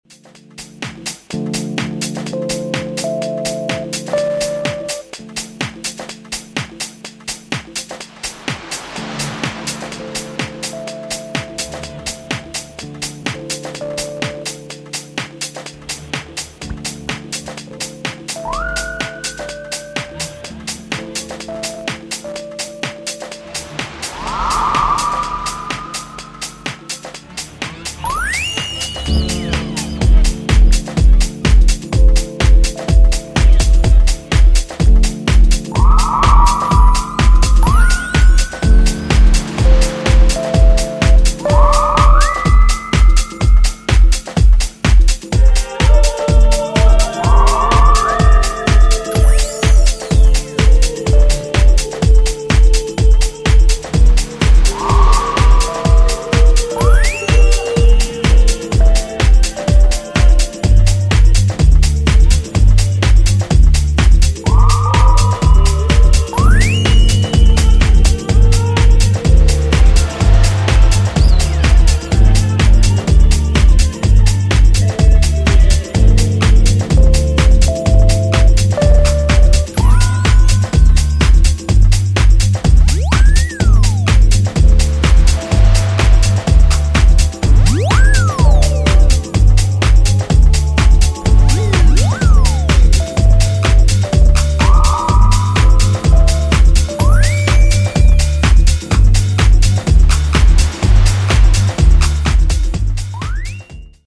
Long format house tracks and tools for you!